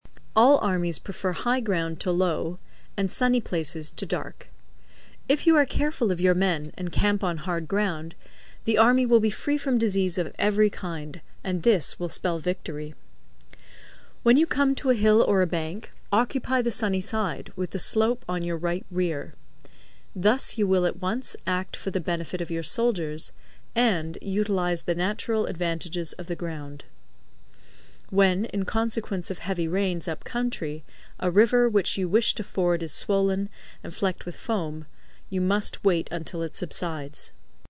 有声读物《孙子兵法》第51期:第九章 行军(2) 听力文件下载—在线英语听力室